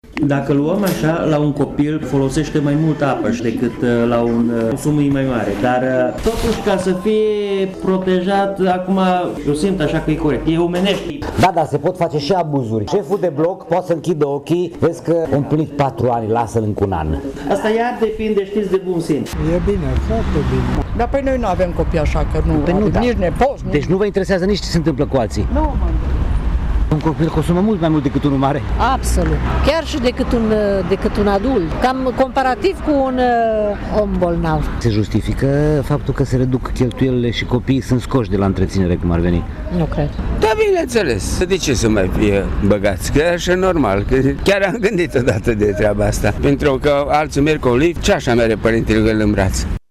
Unii târgumureșeni spun că e o măsură bună, dar că pot apărea abuzuri din partea asociațiilor de proprietari. Există și persoane care susțin că pentru copiii mici se cheltuiește mai mult cu apa și încălzirea, chiar decât pentru un matur.